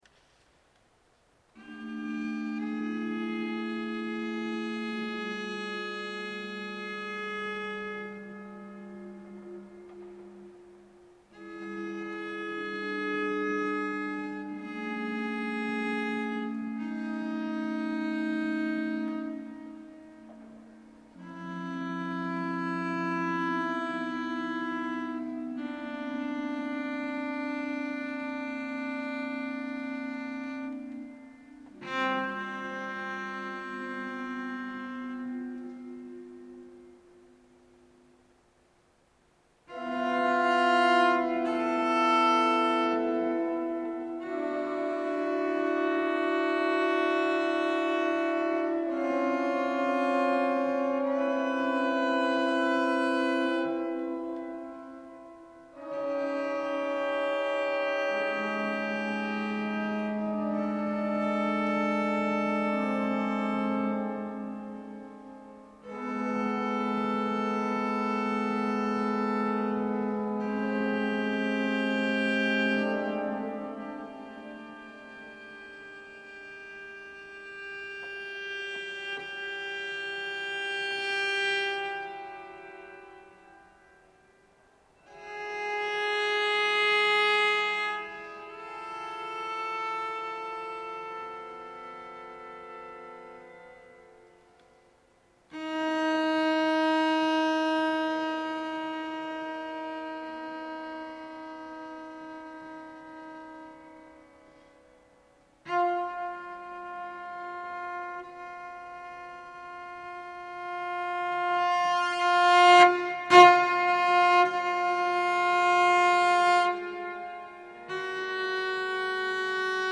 für Violoncello und Orgel, 21'